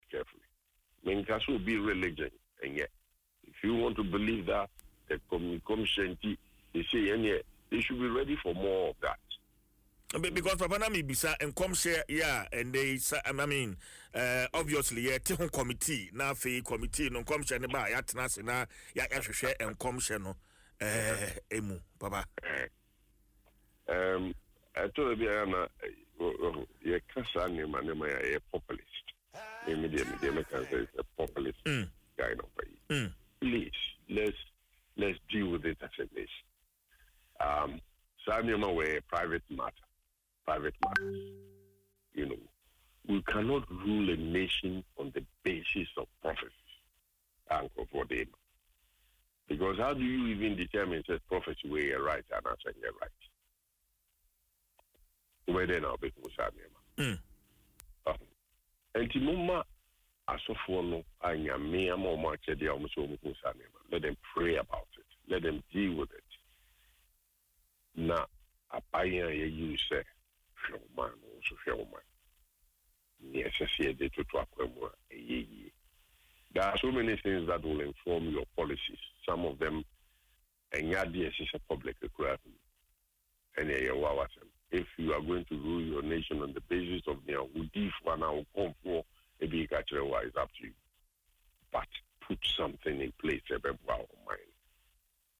Speaking on Adom FM’s Dwaso Nsem, Prof. Asante stressed that prophecies are a private spiritual matter.